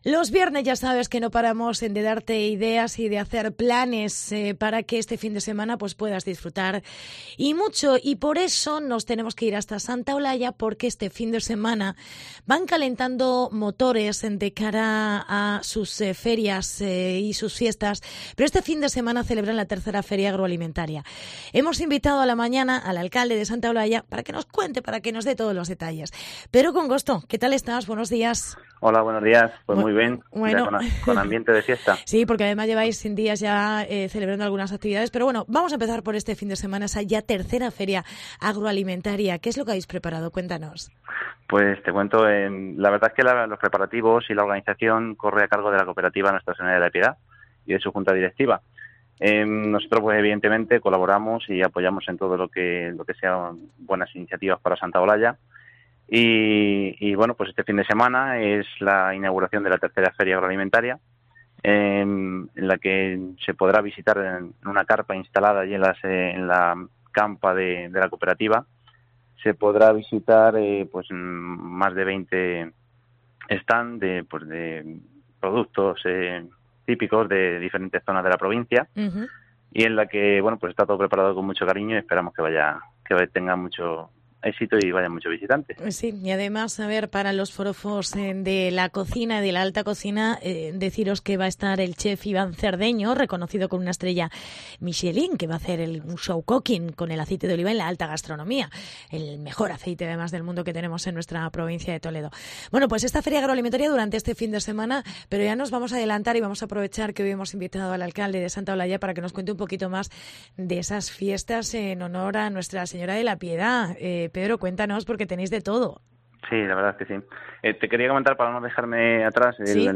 Entrevista con el alcalde: Pedro Congosto